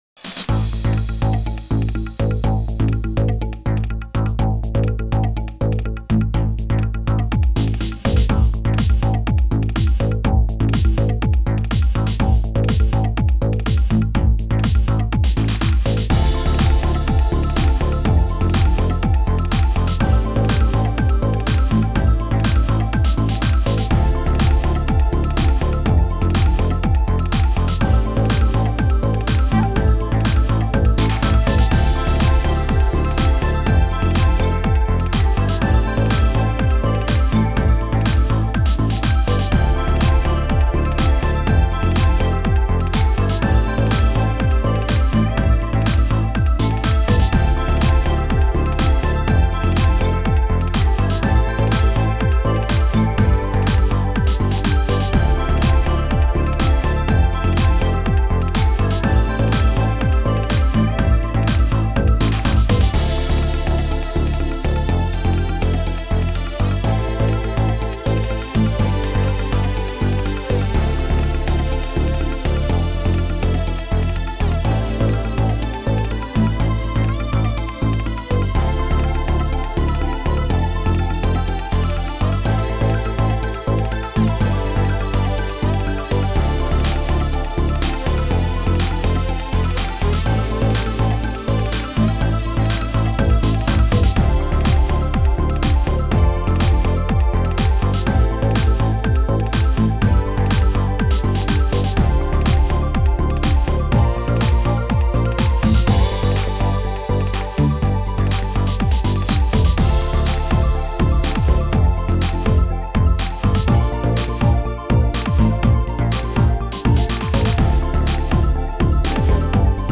Style: Synthpop